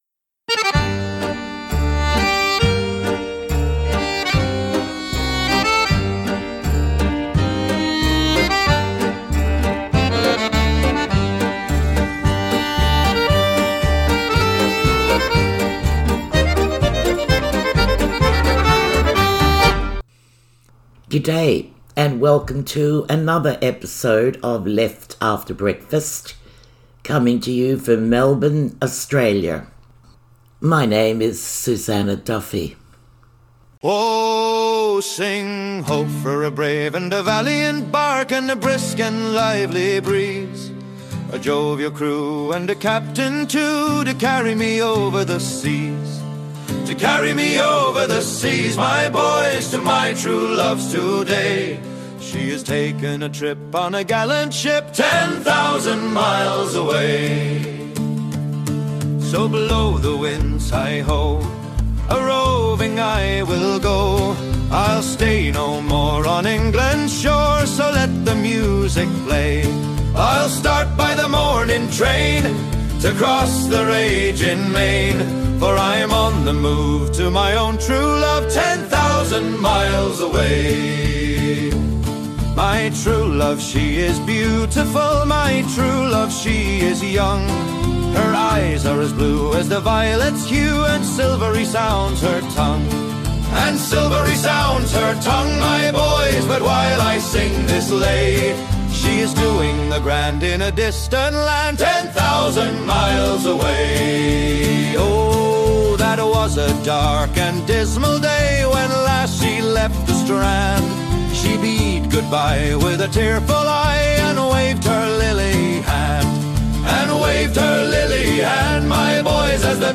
Chains and refrains, the felon chorus. Songs of the poor souls transported to the penal settlements of Australia